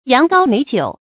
羊羔美酒 注音： ㄧㄤˊ ㄍㄠ ㄇㄟˇ ㄐㄧㄨˇ 讀音讀法： 意思解釋： 羊羔：酒名，因釀制材料中有羊肉，故名。